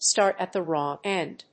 アクセントstárt at the wróng énd